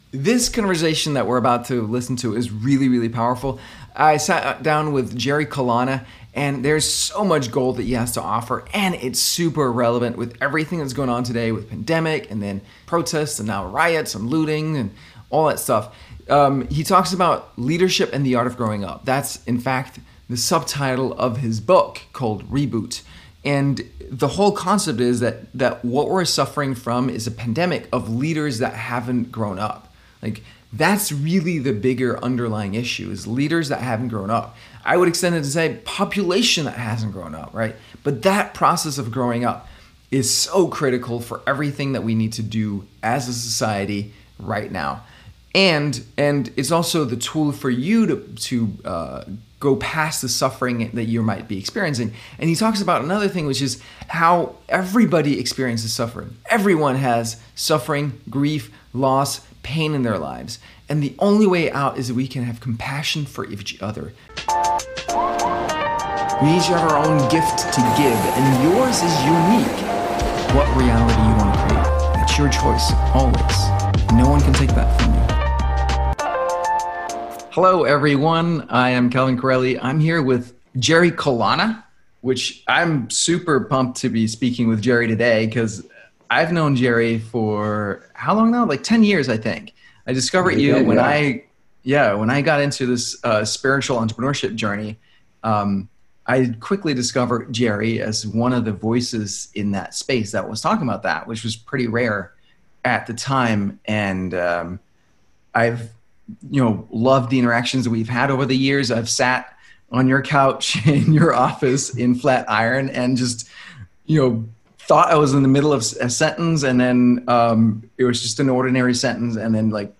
Leadership and The Art Of Growing Up Interview